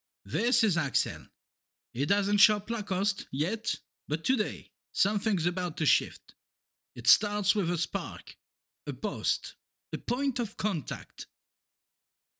Lacoste audition english
25 - 50 ans - Baryton